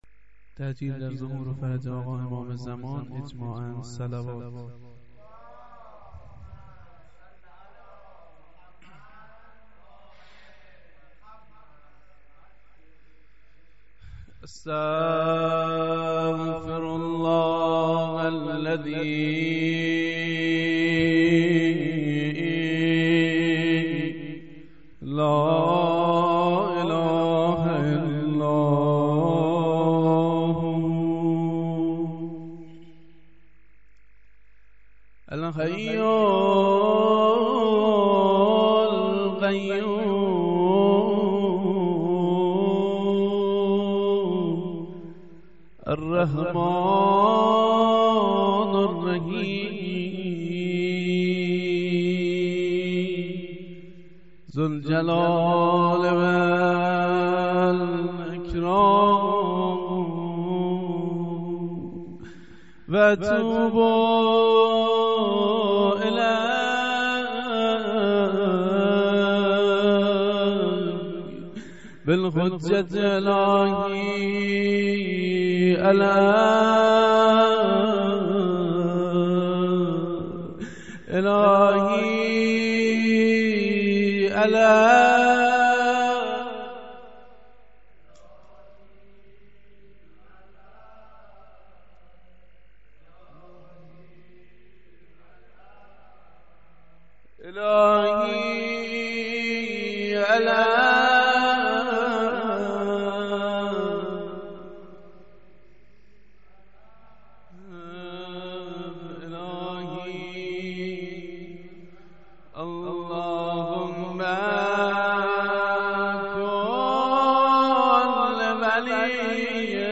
جلسه زیارت عاشورای هفتگی هیئت شهدای گمنام93/06/03